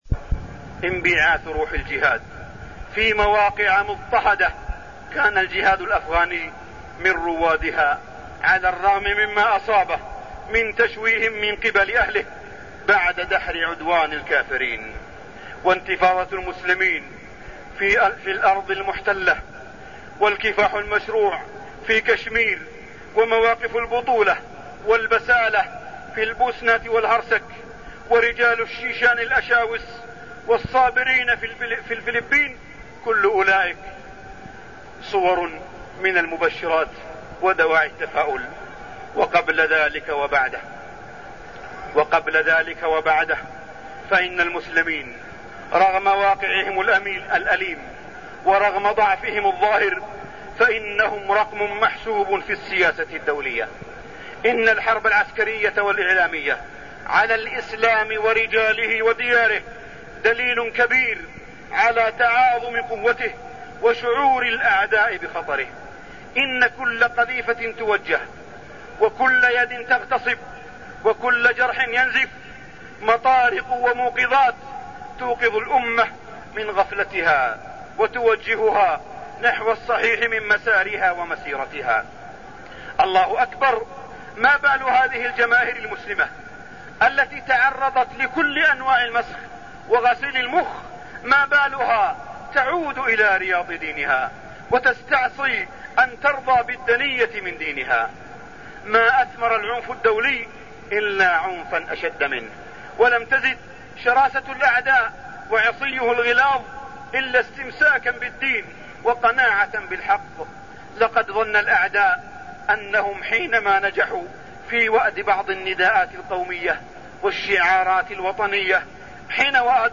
تاريخ النشر ١٠ ذو الحجة ١٤١٥ هـ المكان: المسجد الحرام الشيخ: معالي الشيخ أ.د. صالح بن عبدالله بن حميد معالي الشيخ أ.د. صالح بن عبدالله بن حميد رعاية حجاج بيت الله